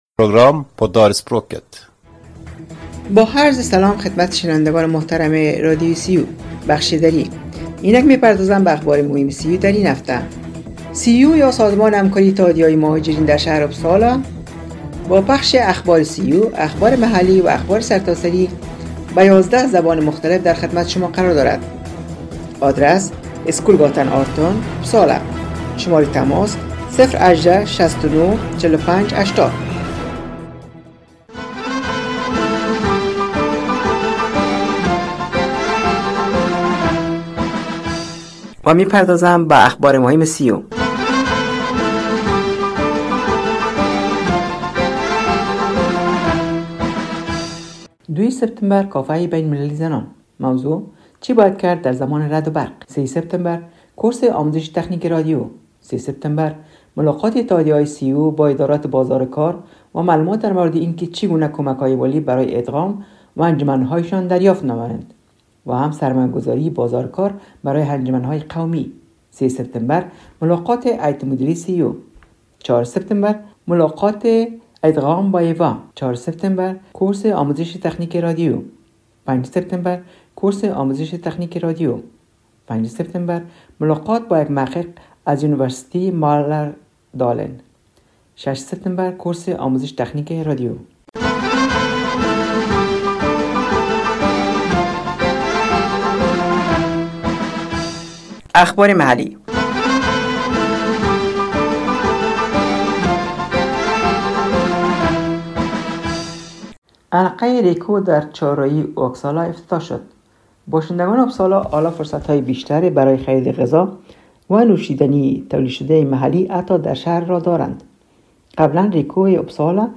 شنوندگان گرامی برنامه دری رادیو ریو یا انترنشنال رادیو در اپسالا سویدن روی موج ۹۸،۹ FM شنبه ها ازساعت ۸:۳۰ تا ۹ شب به وقت سویدن پخش میگردد که شامل اخبار سیو، اخبارمحلی و اخبارسرتاسری میباشد